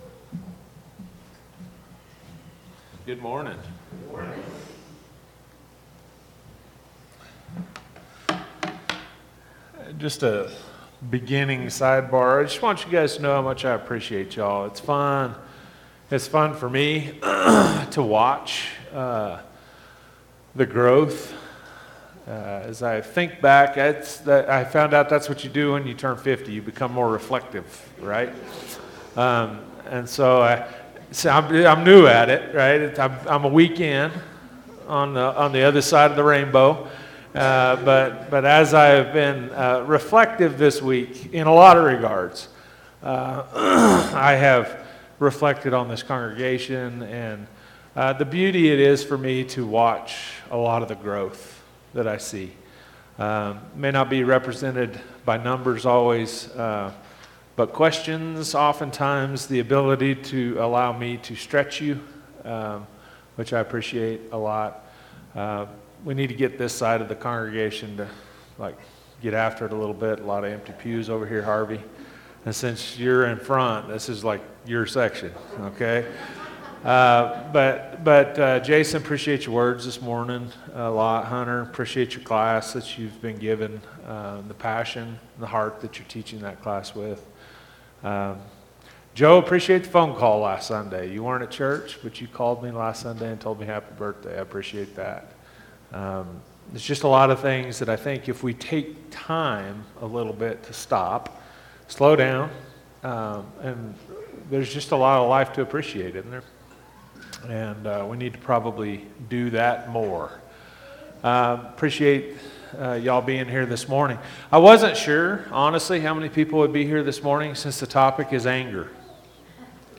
Sunday AM sermon